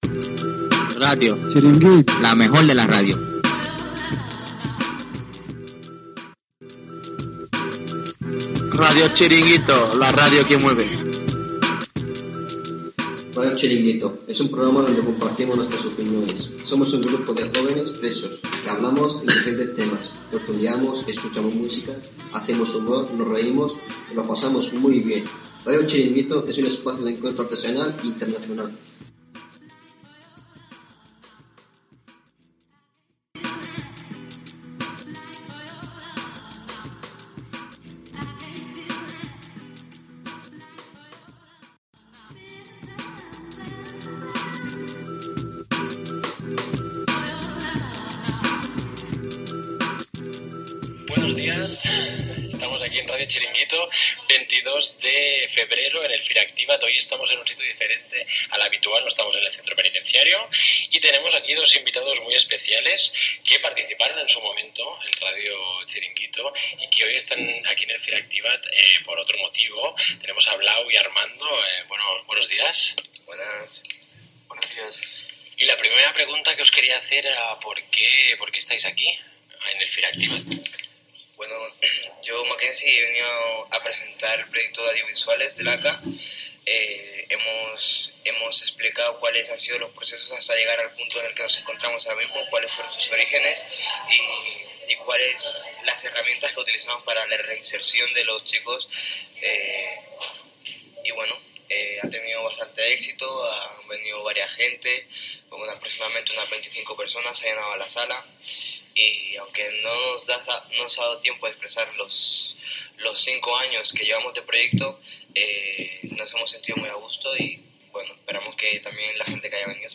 Identificació de l'emissora
Ràdio del Centre Penitenciari de Joves de Barcelona.